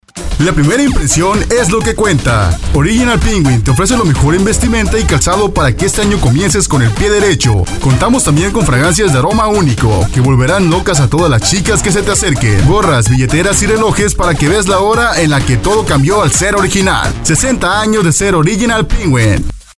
Amateur voice actor/announcer
Sprechprobe: Industrie (Muttersprache):